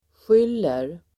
skylla verb, blame Grammatikkommentar: A & (x) på B/y/att+SATS Uttal: [sj'yl:er] Böjningar: skyllde, skyllt, skyll, skylla, skyller Definition: ge (någon) skulden Exempel: skyll dig själv!